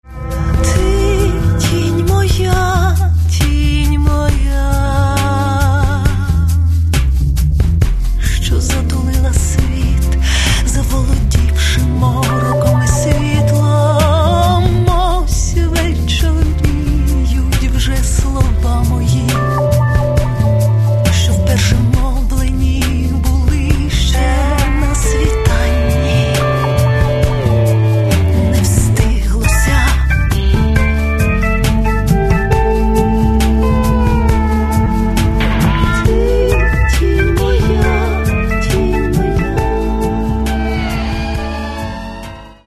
Каталог -> Рок та альтернатива -> Поетичний рок